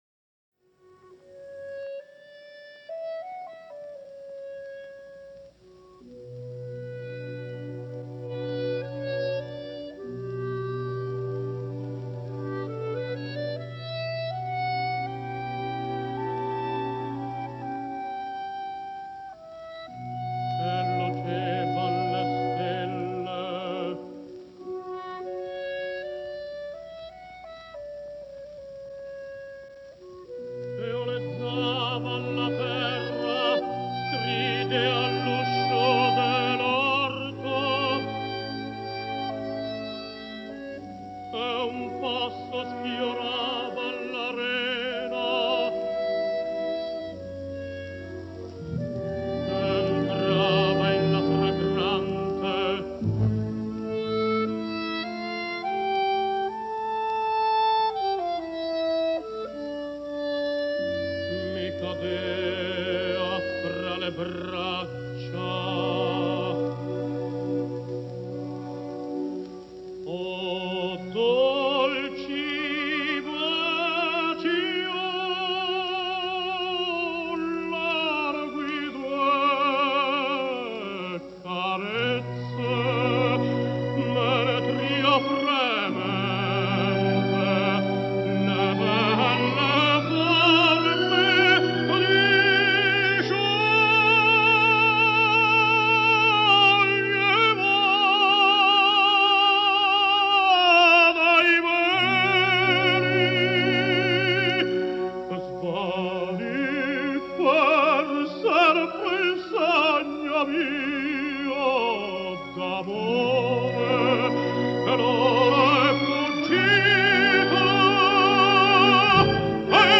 Жанр: Opera